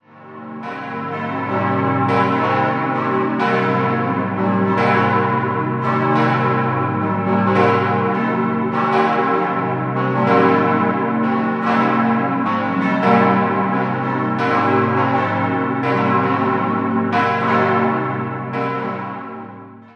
Idealquartett g°-b°-c'-es' Alle Glocken wurden 1933 vom Bochumer Verein für Gussstahlfabrikation gegossen und bilden das tontiefste Gussstahlgeläut Bayerns.